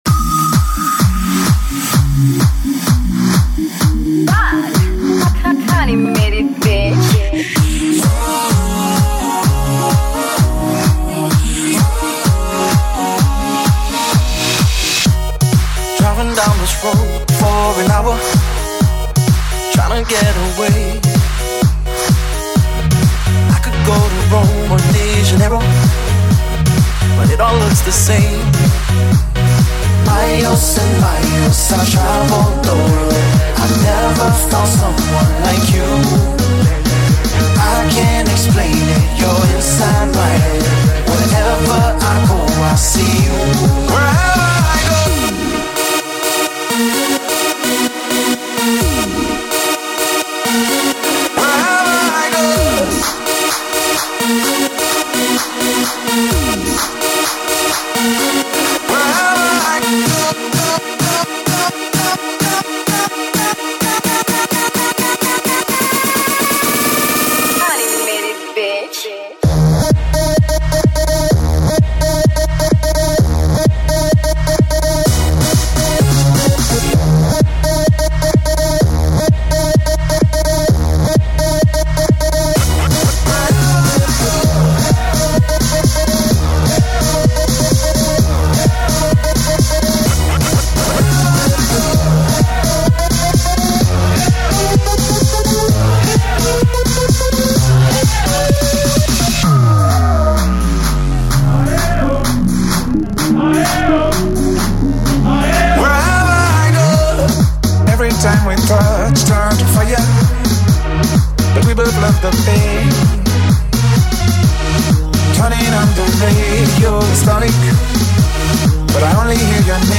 Genre: TECHNOMIX.